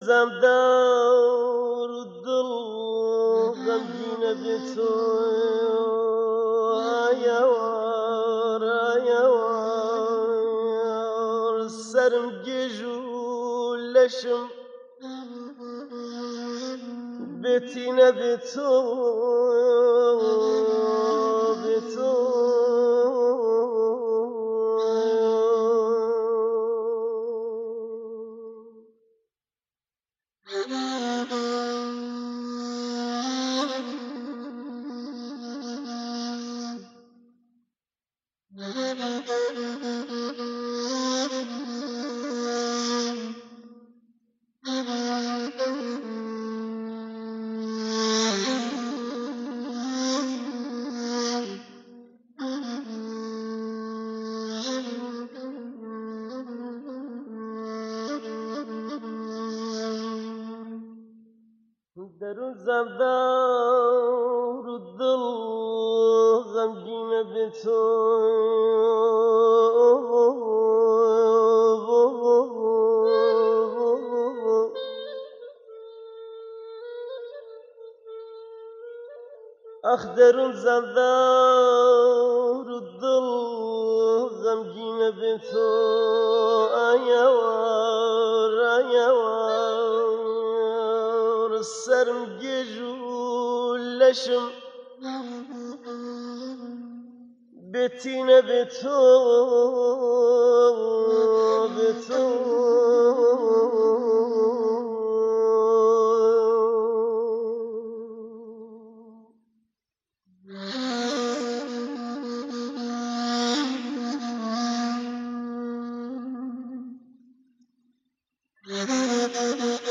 ملۆدییەکی کوردی ببیسن